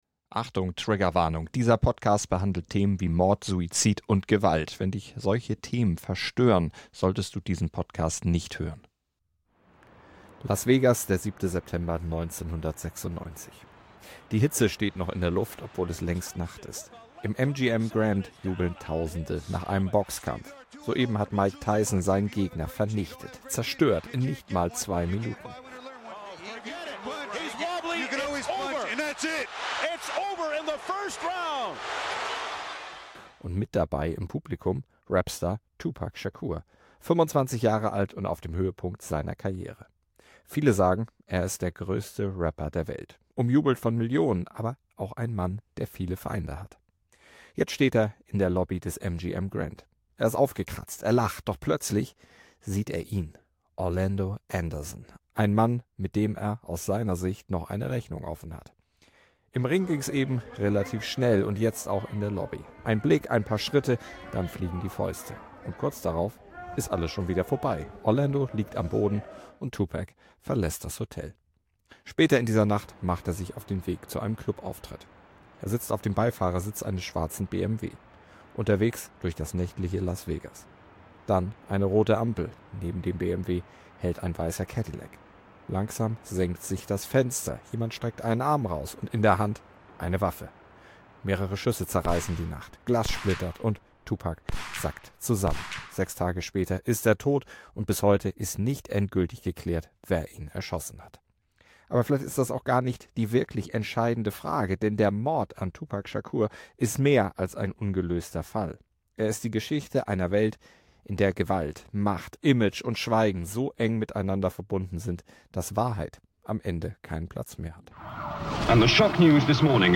O-Töne wurden in diesem Podcast mit Hilfe von KI-Stimmmen overvoiced.